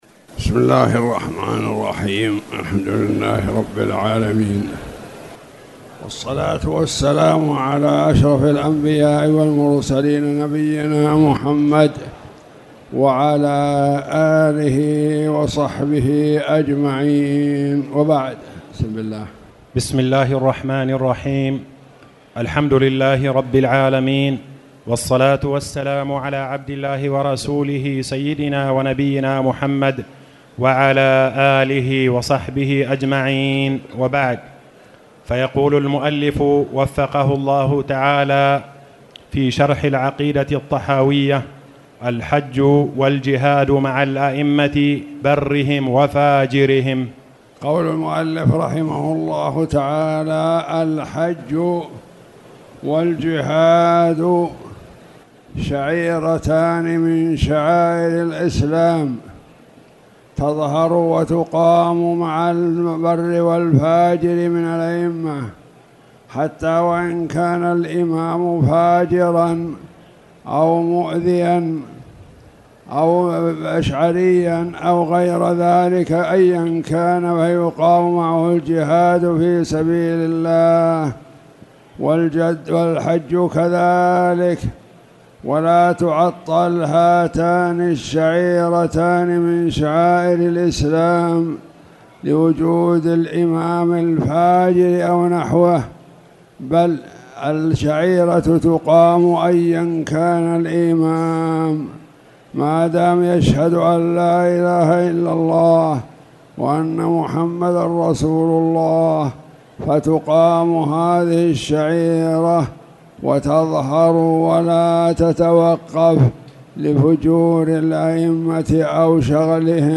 تاريخ النشر ١٣ شعبان ١٤٣٨ هـ المكان: المسجد الحرام الشيخ